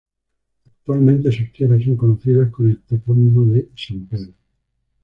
co‧no‧ci‧das
/konoˈθidas/